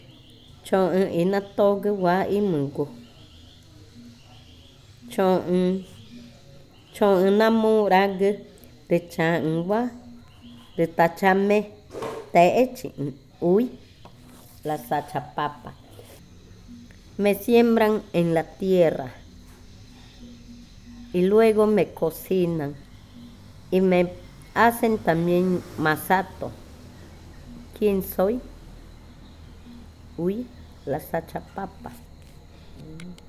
Adivinanza 25. Sachapapa
Cushillococha